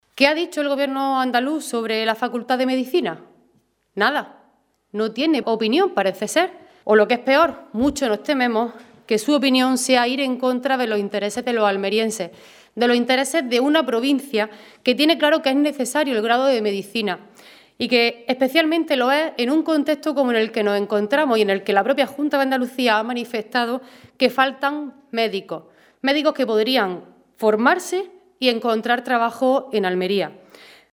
En rueda de prensa, la diputada socialista ha calificado de “intolerable” que el Partido Popular haya tenido “la desvergüenza” de “ponerle tareas” a la Universidad de Almería para que pueda conseguir su facultad de Medicina, al tiempo que el Gobierno andaluz “se mantiene callado o, incluso, llega a decir lo contrario, como ha hecho el consejero de Universidades, Rogelio Velasco, al sugerir que existe un exceso de cargos y titulaciones”.